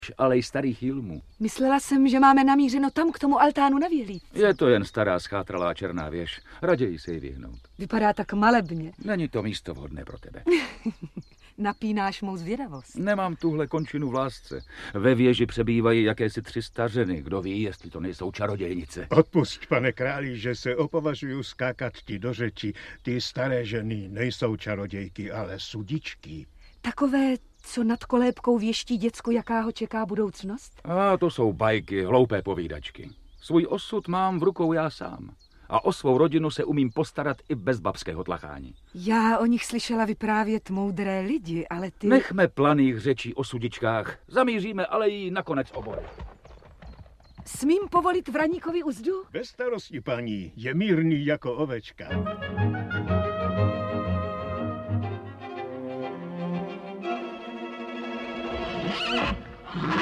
Audiobook
Read: Eliška Balzerová